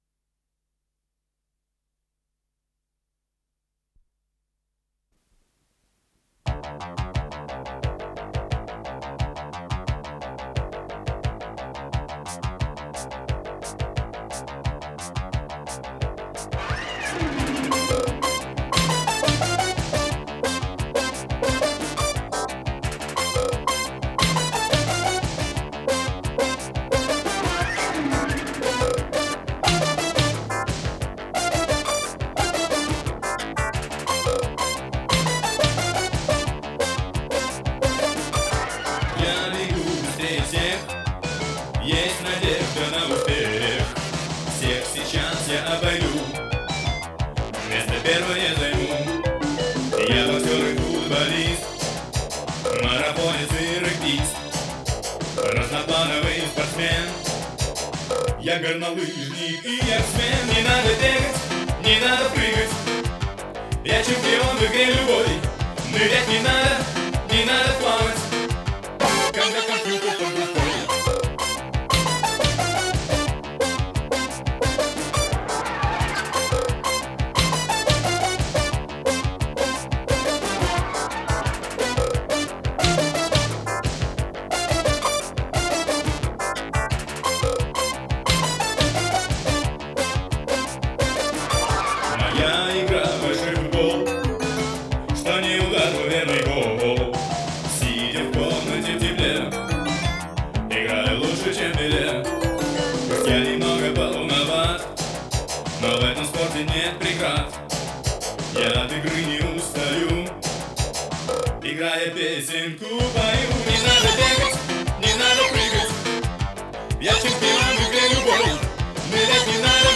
РедакцияДетская
пение
ВариантДубль моно